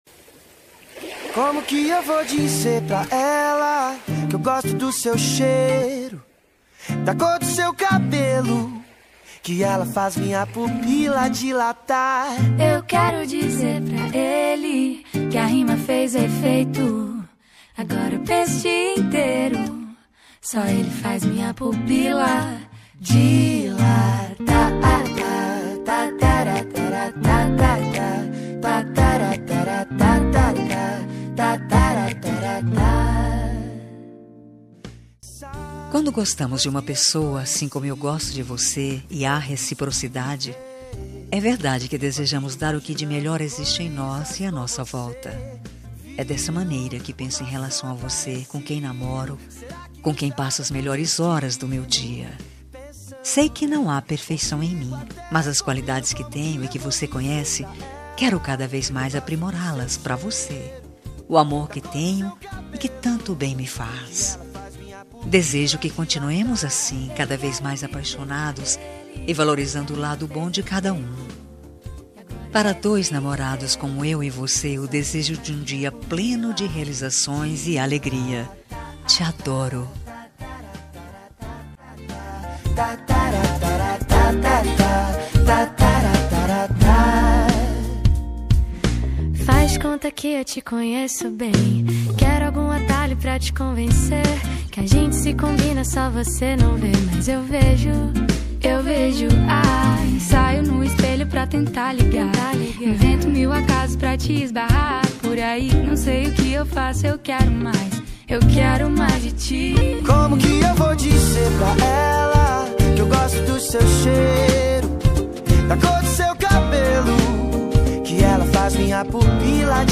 Dia dos Namorados – Para Namorado – Voz Feminina – Cód: 6853